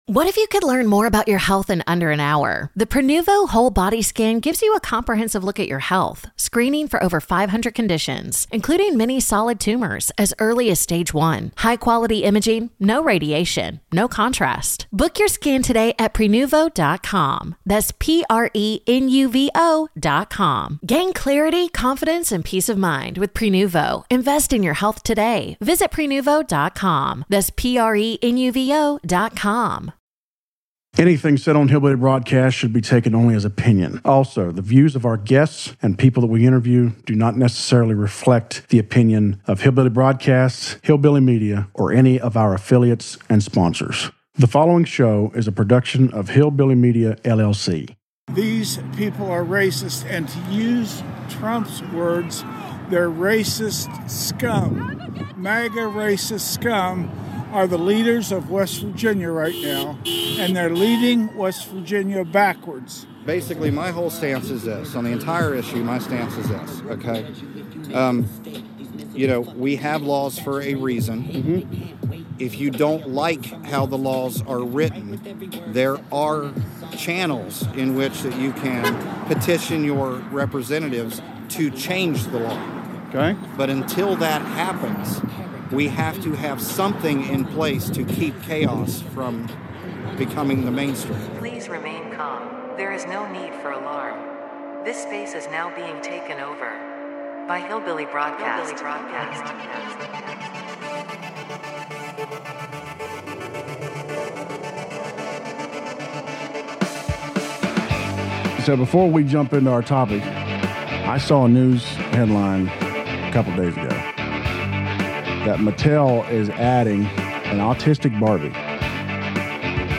Be a guest on this podcast Language: en Genres: Comedy , Comedy Interviews Contact email: Get it Feed URL: Get it iTunes ID: Get it Get all podcast data Listen Now...